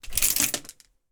household
Cloth Hanger Movement 2